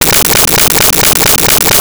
Arcade Movement 03.wav